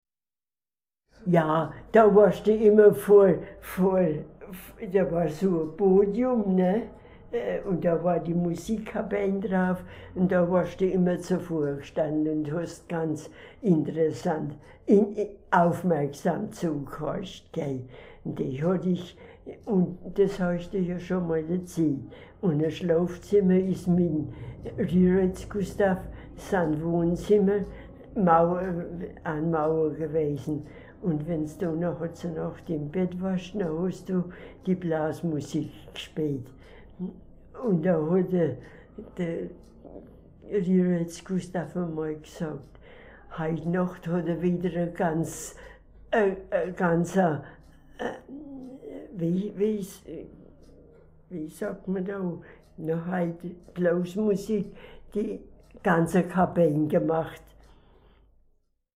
blasmusik.mp3